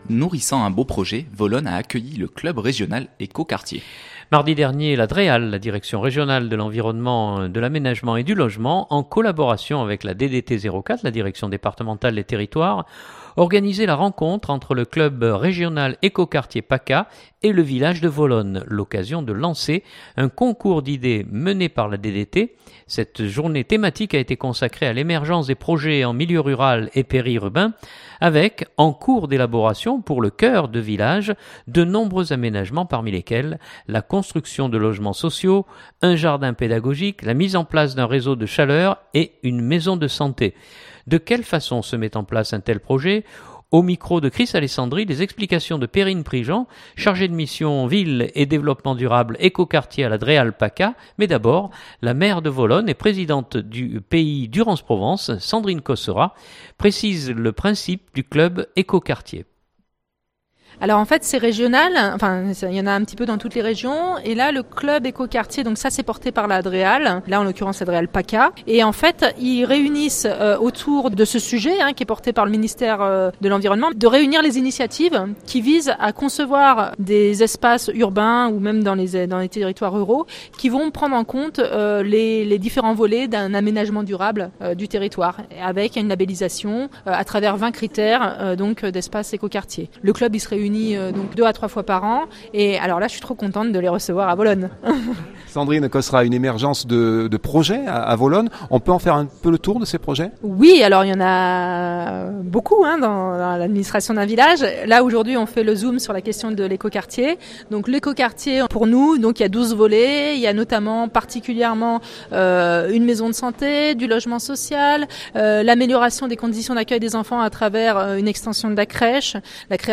Journal du 2016-11-23 Volonne.mp3 (3.51 Mo)
mais d’abord, la Maire de Volonne et Présidente du Pays Durance-Provence Sandrine Cosserat précise le principe du club Eco-Quartier.